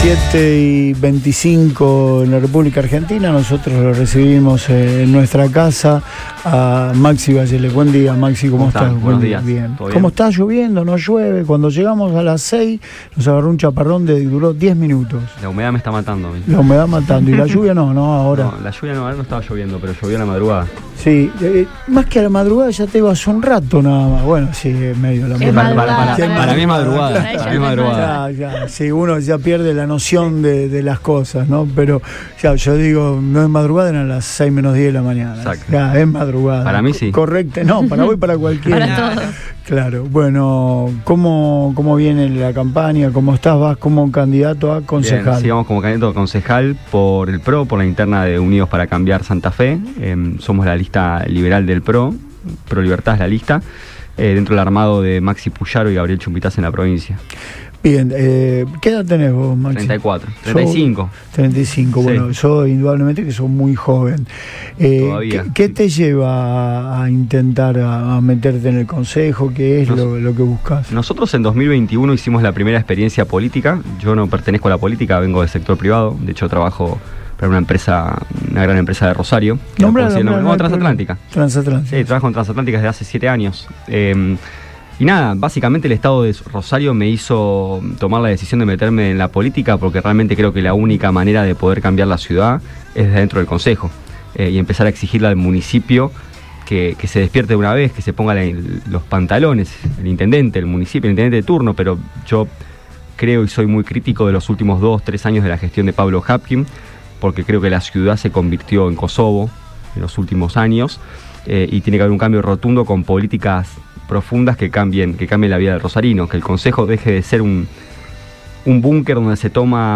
pasó por los estudios de Radio Boing